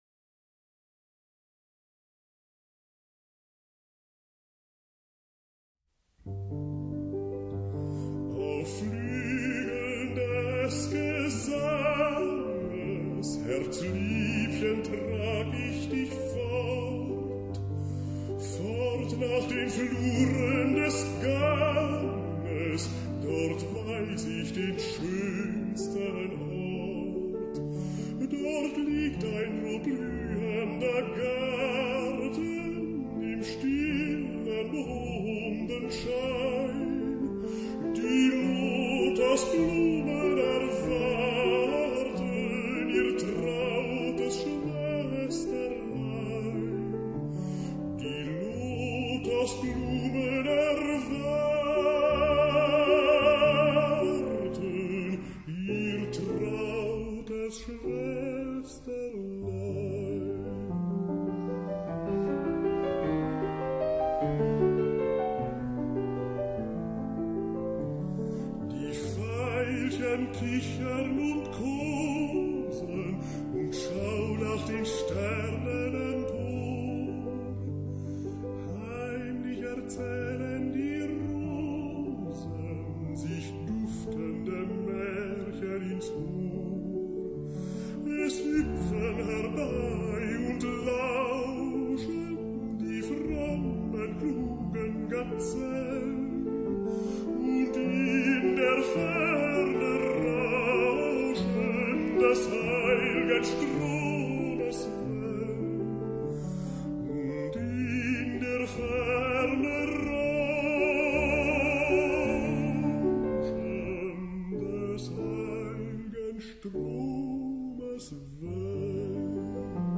La versió per a mi quasi referencial del tot, deguda a Victoria de los Ángeles la vaig desestimar per estar acompanyada en un preciós arranjament orquestral que semblava que donava prioritat a l’elecció, i per això em vaig estimar més que totes les versions fossin acompanyades a piano.